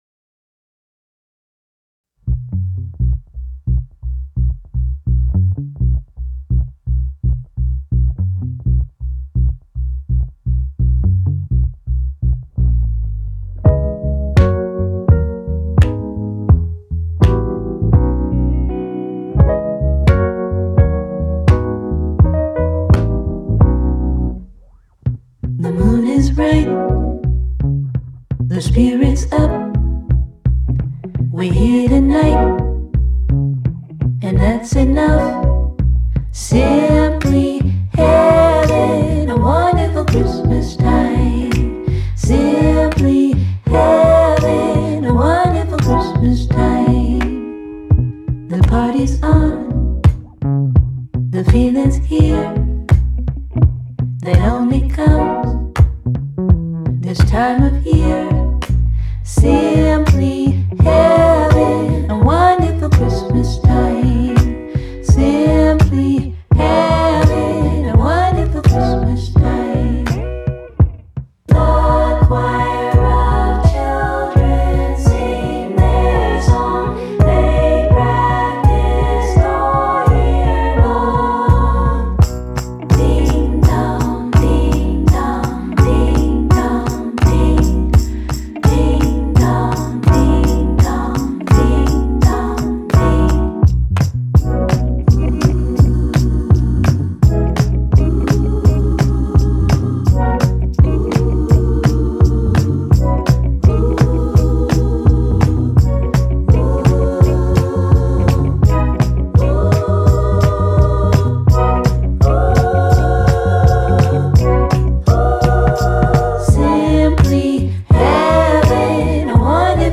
メインストリーム、オルタナティブR&Bを軸に、HIP HOP、BEAT MUSICまで幅広く取り込み
冬の街に流れる暖かな光景と呼応するように、しなやかで暖色のテクスチャーが全編に漂います。